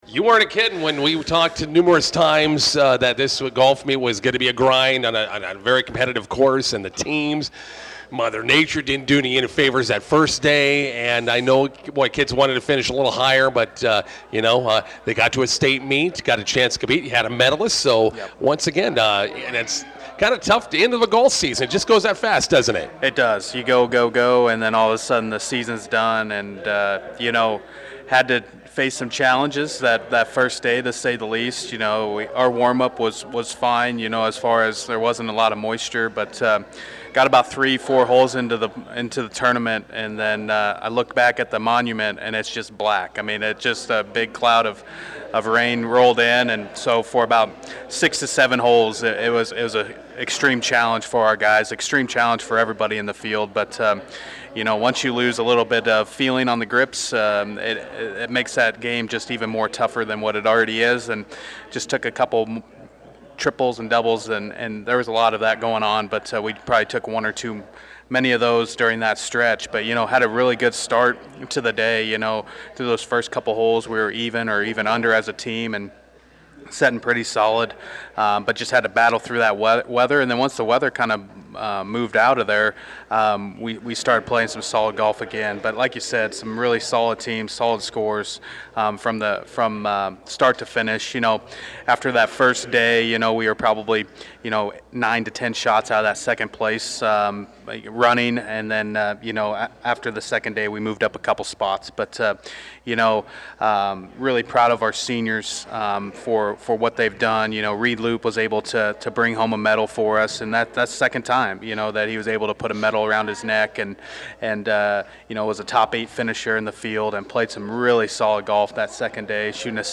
INTERVIEW: Bison boys golf finish a fantastic season at the Class B state tournament.